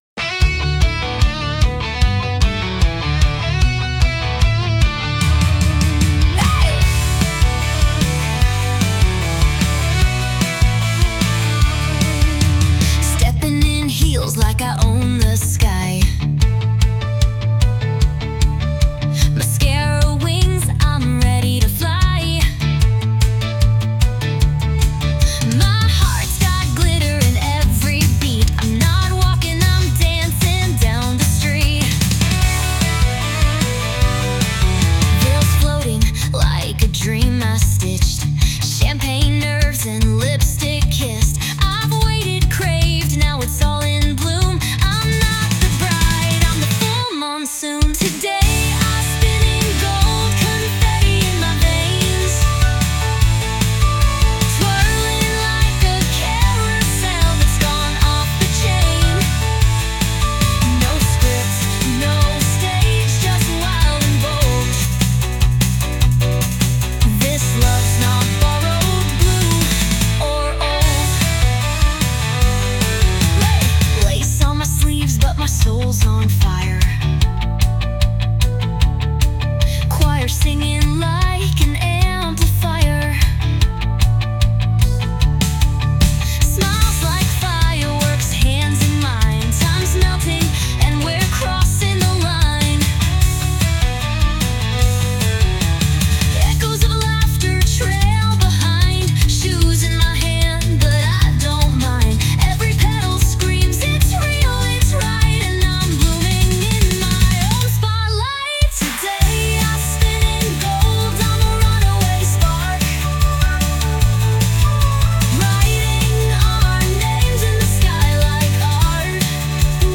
女性ボーカル洋楽洋楽 女性ボーカルオープニングムービー退場余興アップテンポロック明るい元気華やか
著作権フリーオリジナルBGMです。
女性ボーカル（洋楽・英語）曲です。